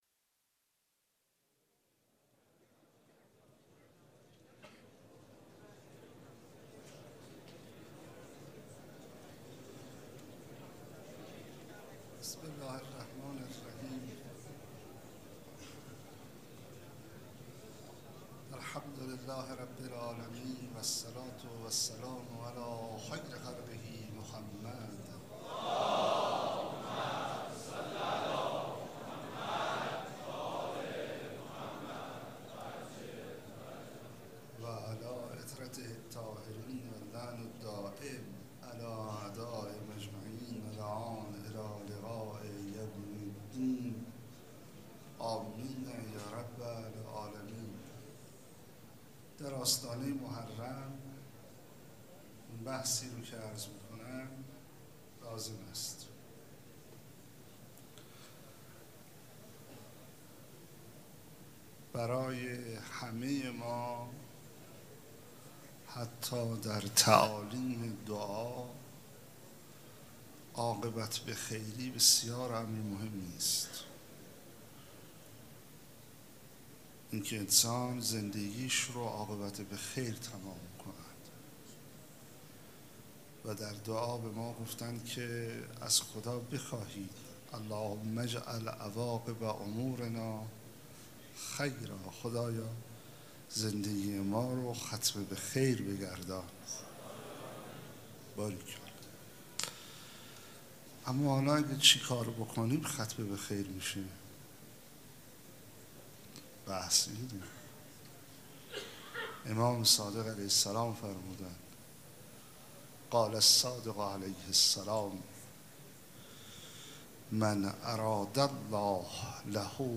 27 شهریور 96 - هیئت ریحانة الحسین - سخنرانی